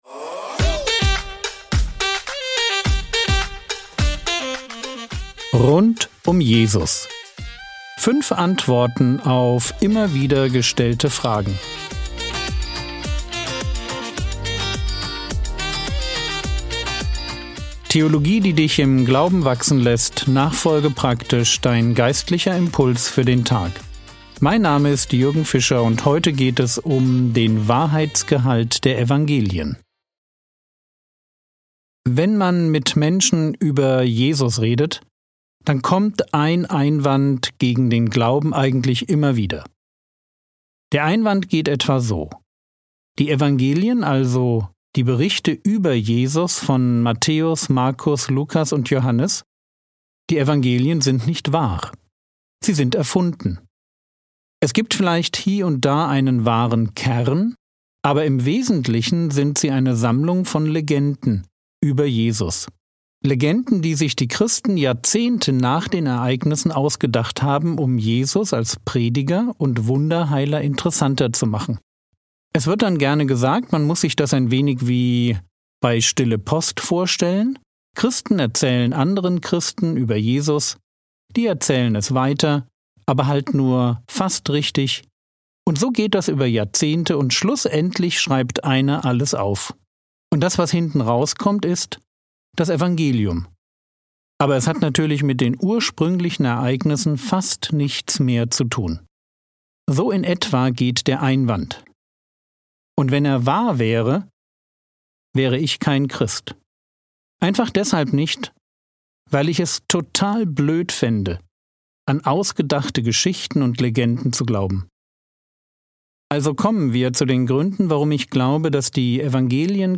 Rund um Jesus (2/5) ~ Frogwords Mini-Predigt Podcast